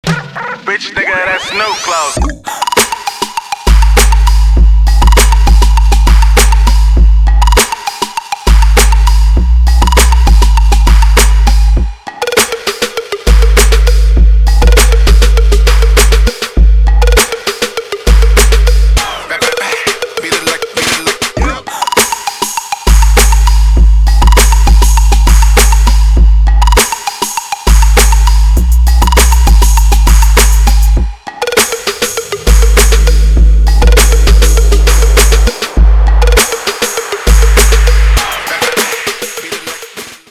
• Качество: 320, Stereo
Trap
Bass